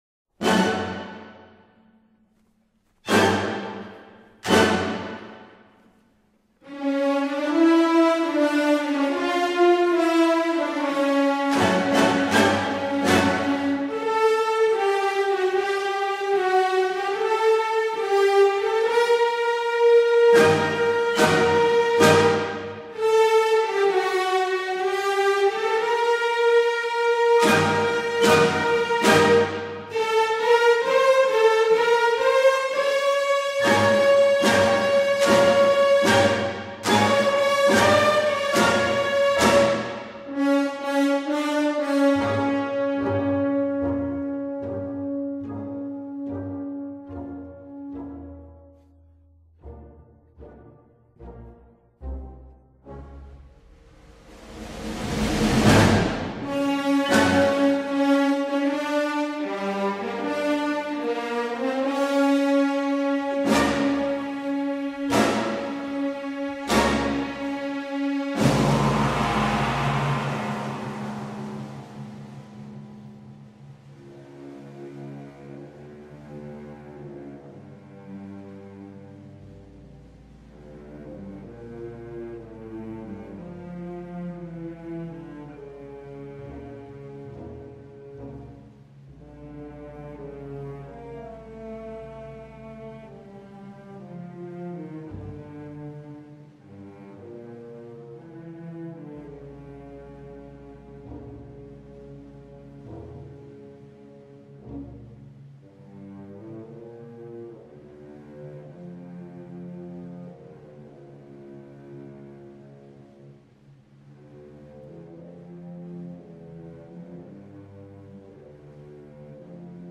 suntuoso dramatismo sinfónico